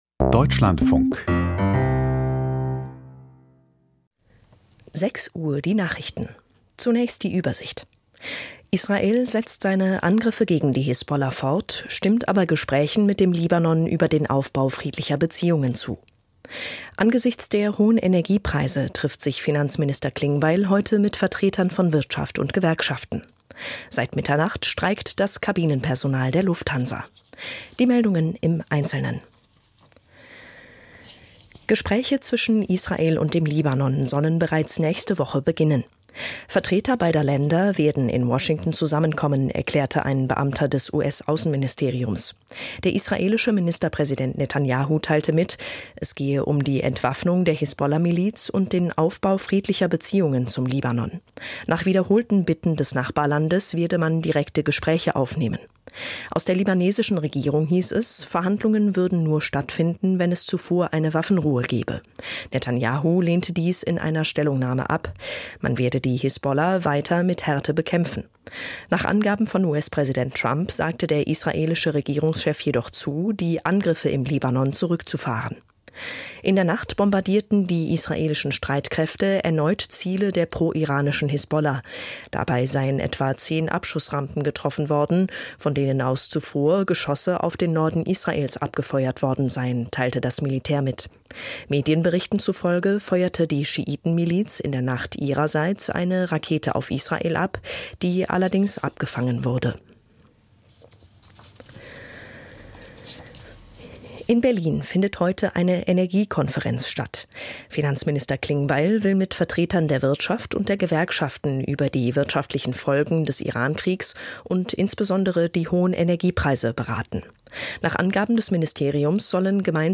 Die Nachrichten vom 10.04.2026, 06:00 Uhr
Aus der Deutschlandfunk-Nachrichtenredaktion.